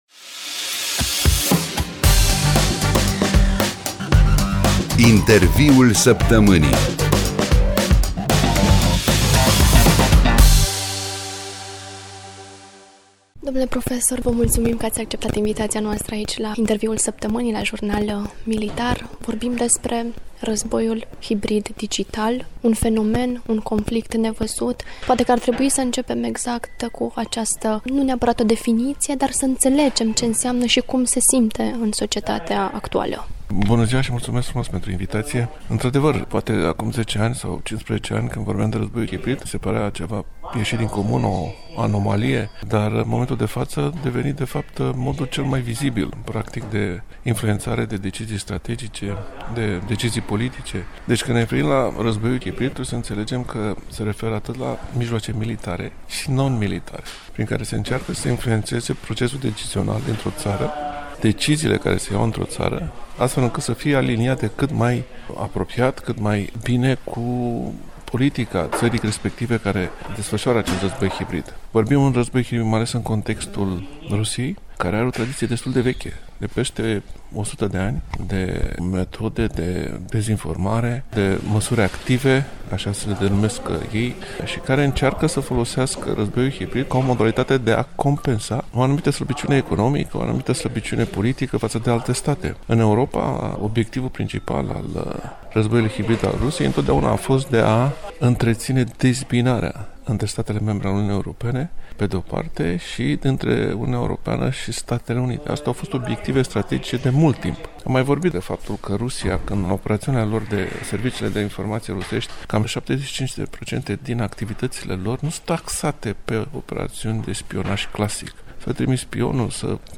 05-Interviul-Saptamanii.mp3